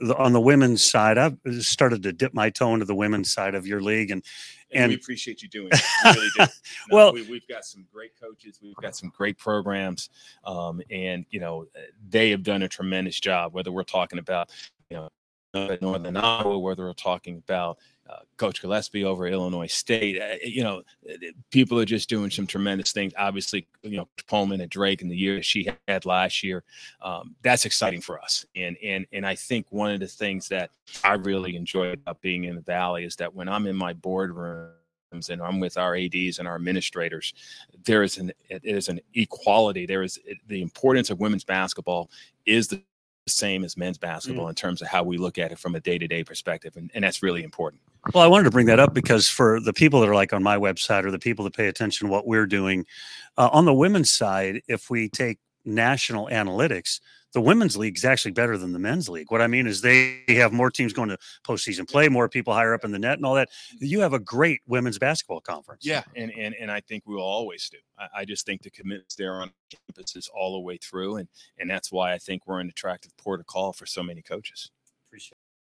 This conversation took place at the Valley men’s basketball media day.